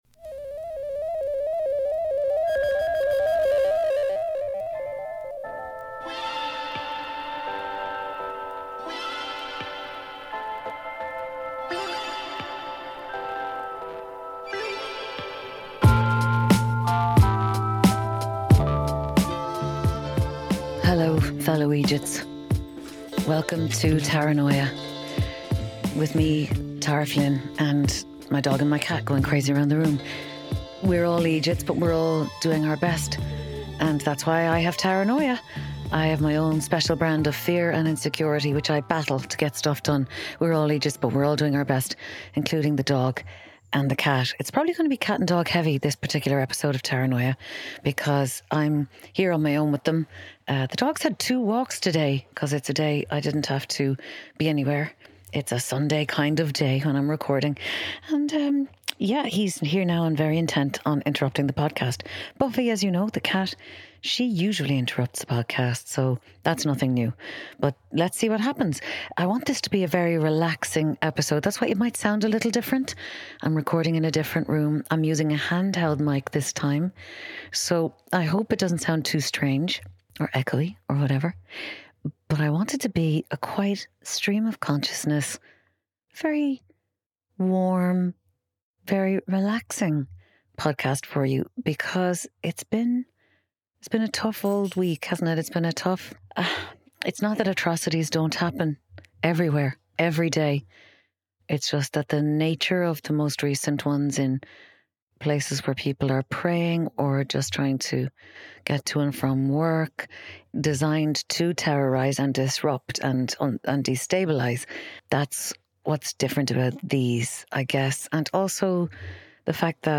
This episode ends with sounds from Cape Reinga, the northern tip of New Zealand and the confluence point of the Pacific Ocean and the Tasman Sea.